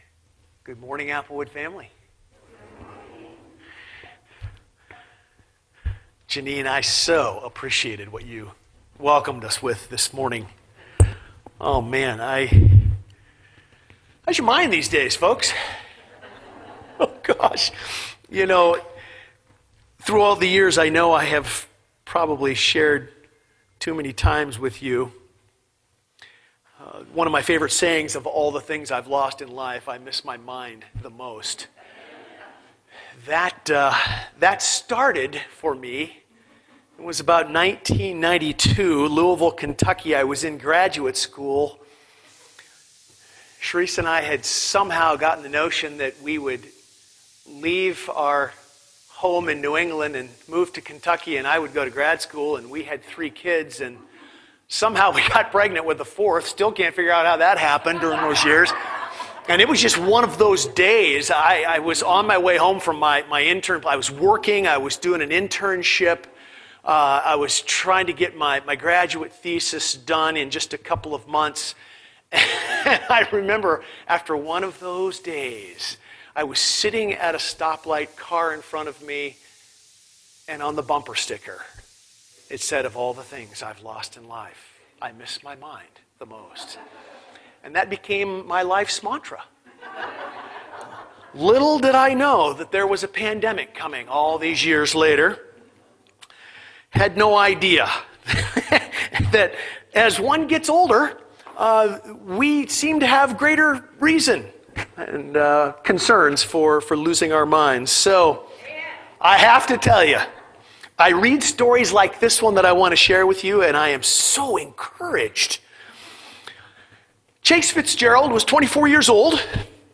sermon - Applewood Community Church - Page 12